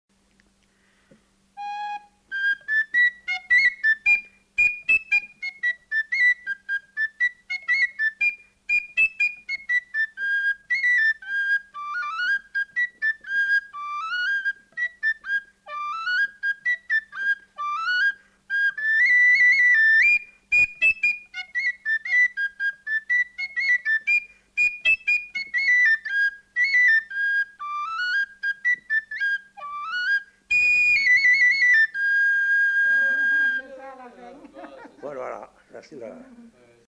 Aire culturelle : Bazadais
Genre : morceau instrumental
Instrument de musique : flûte à trois trous
Danse : rondeau